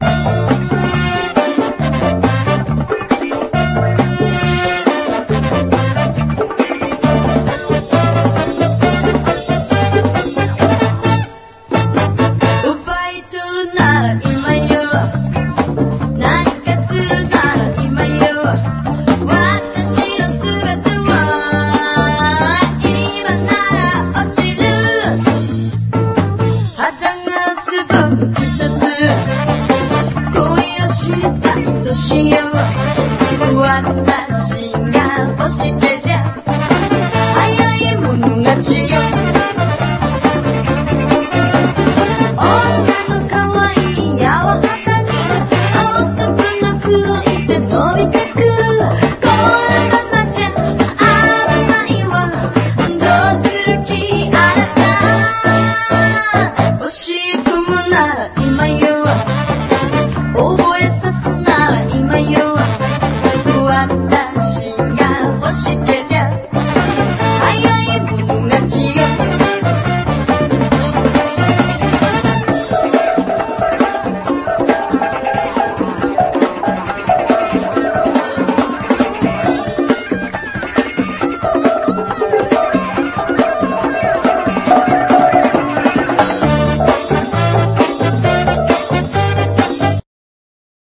SOUL / SOUL / 60'S / DEEP SOUL / SOUTHERN SOUL / FUNKY SOUL
レア・ディープソウル＆ファンキー・ソウル！